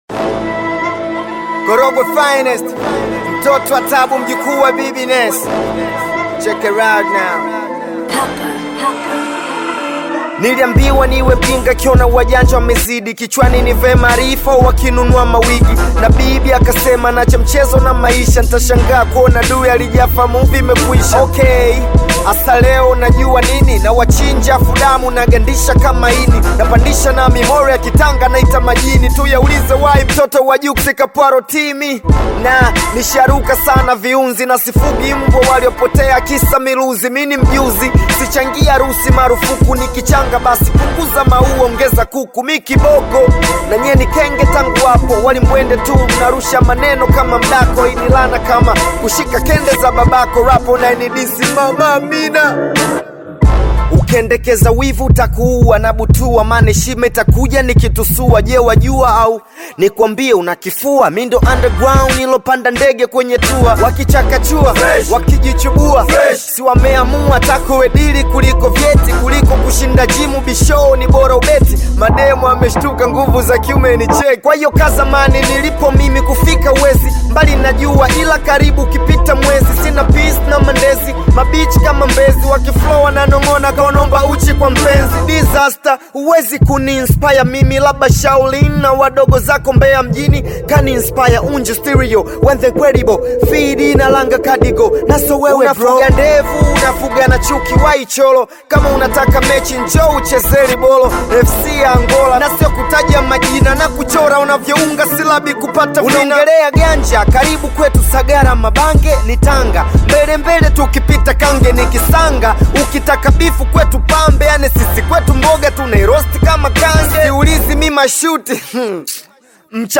Bongo Flava
hip-hop music
African Music